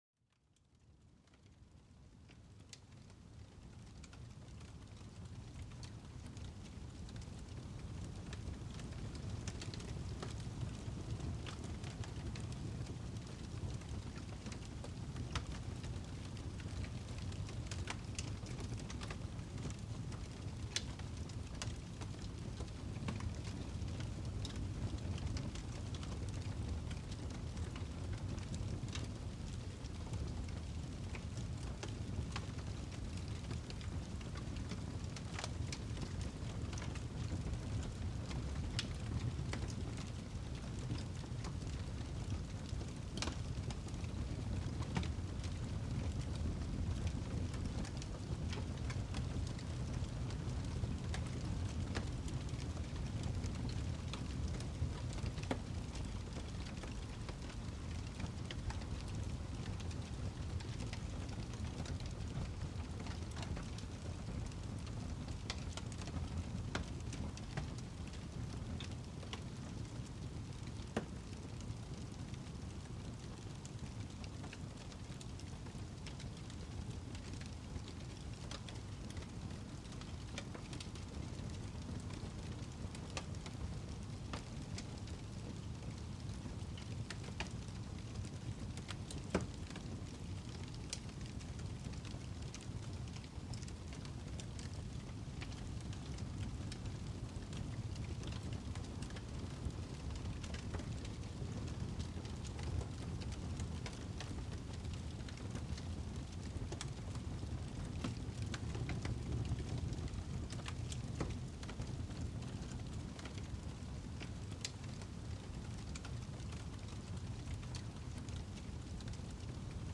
Sonidos de fuego para una relajación profunda y una mente en calma
Déjate envolver por el suave crepitar de un fuego, trayendo calidez y tranquilidad a tu mente. Cada chispa y cada soplo de las llamas invita a la relajación, liberando tensiones profundamente.